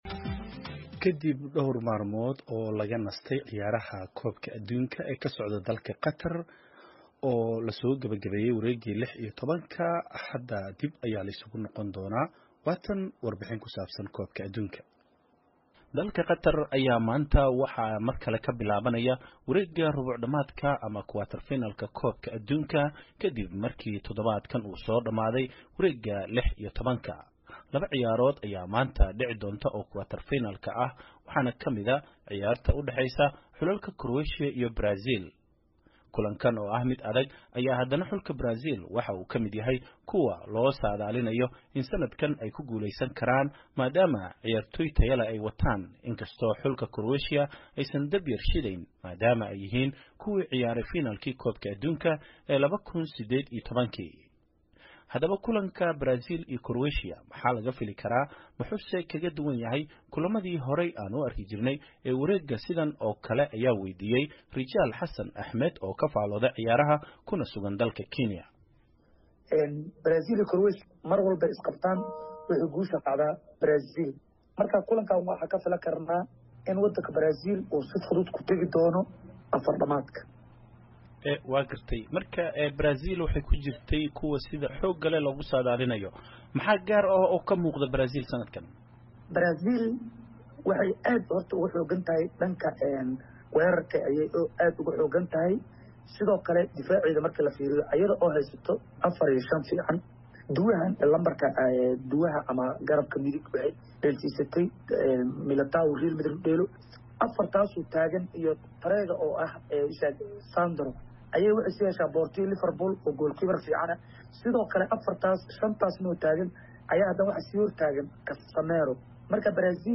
Haddaba kooxaha isku soo haray iyo ciyaaraha maanta dhacaya waxaa warbixintan nooga diyaariyay wariyaha VOA